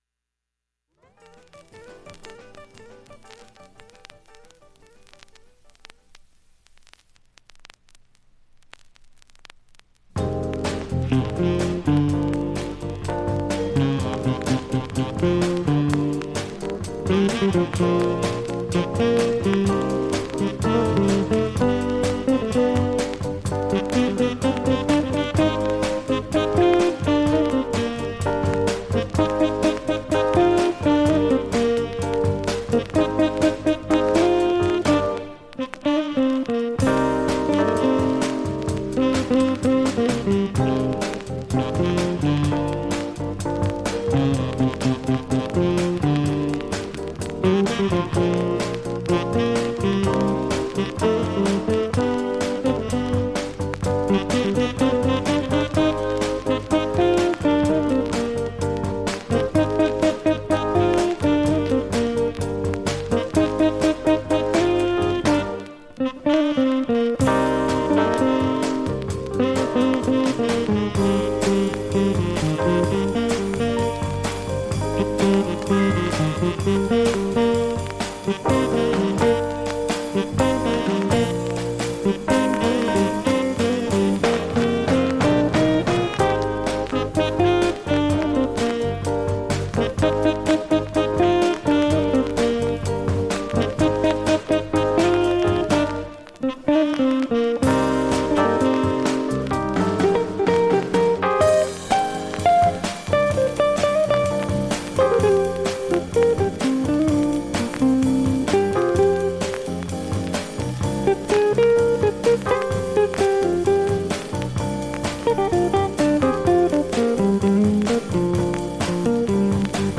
バックチリ小さめなので、
下記プツ音も問題の無いレベルです。
周回プツ出ますがかすかです。
3分30秒の間に周回プツ出ますがかすかで、
10回までのかすかなプツが２箇所
７回までのかすかなプツが９箇所
単発のかすかなプツが３箇所